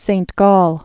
(sānt gôl, gäl, säɴ gäl)